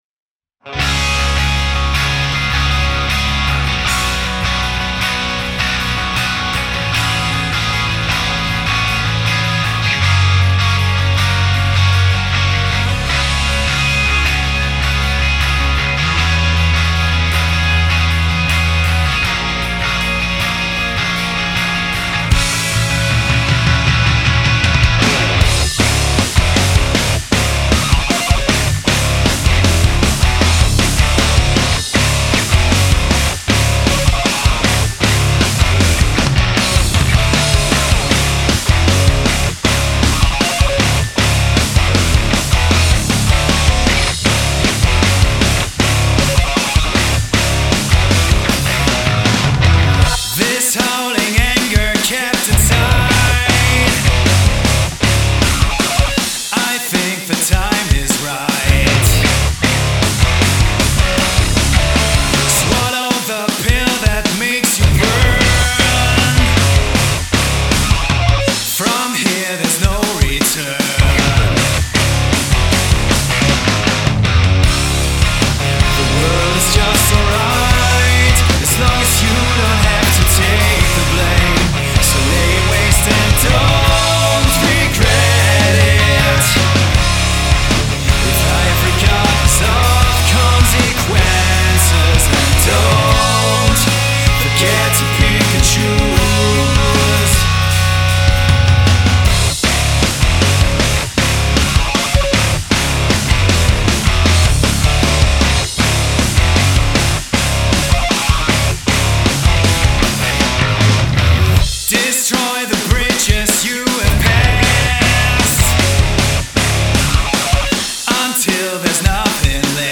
Rock
Попробывал свести используя только Acustica Audio.